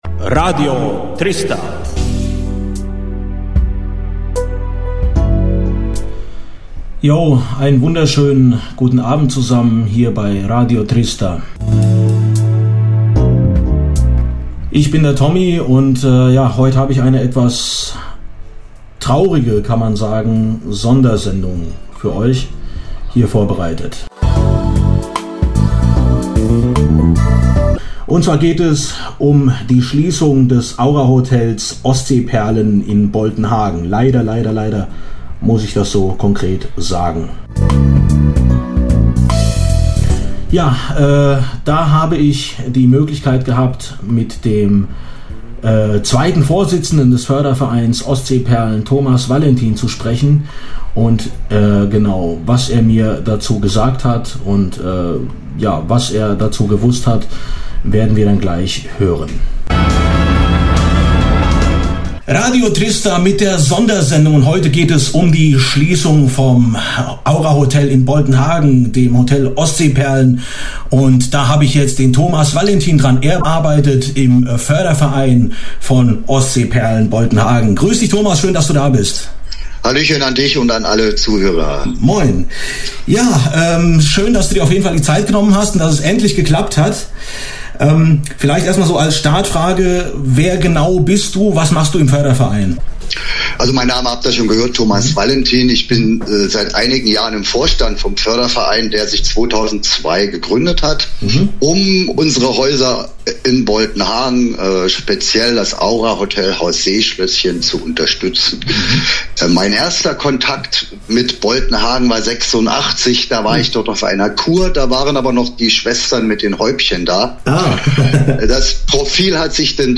Radio Trista - News: Interview zur Schliessung des Aurahotels in Boltenhagen